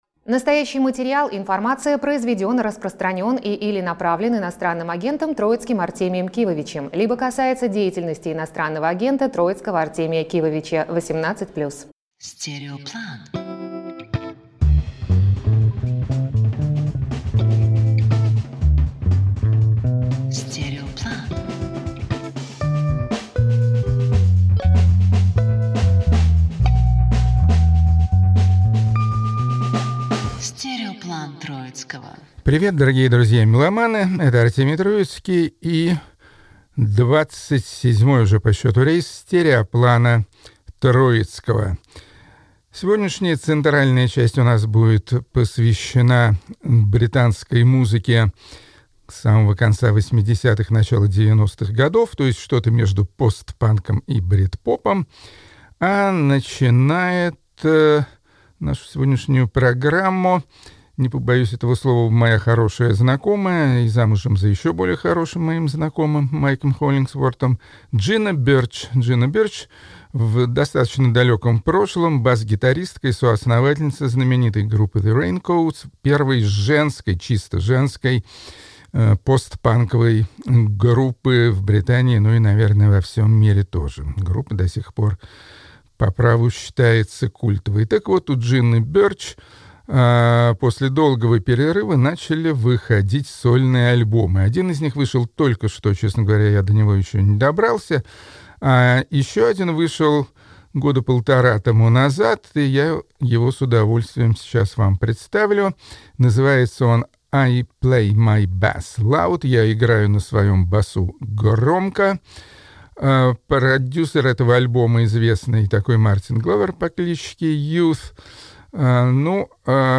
Авторская программа Артемия Троицкого